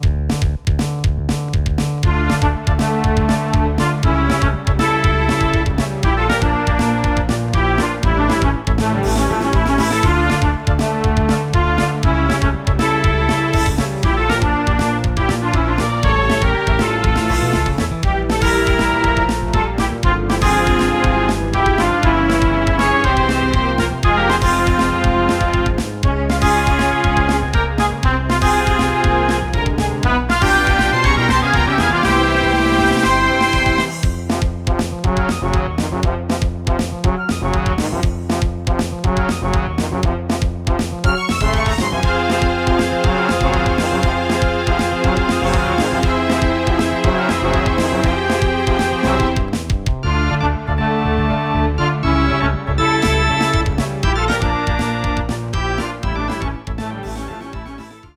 musical sketche with some inspiration of Castlevania music.